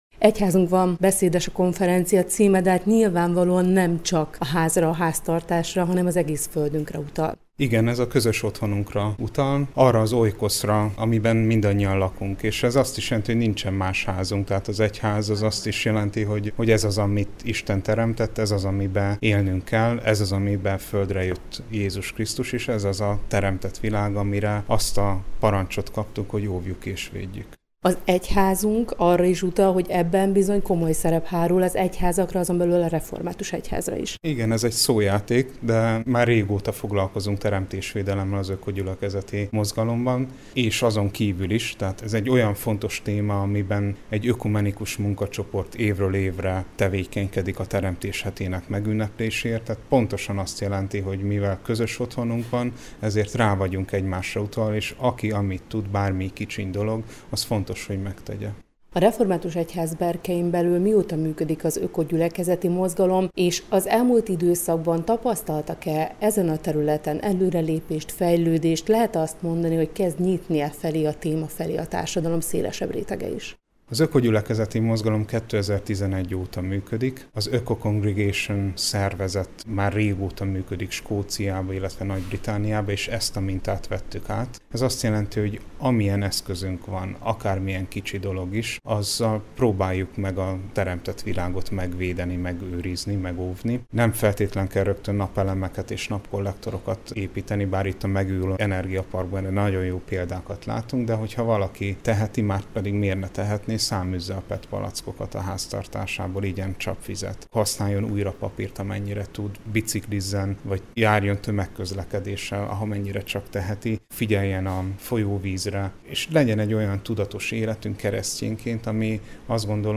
A konferencia megnyitóján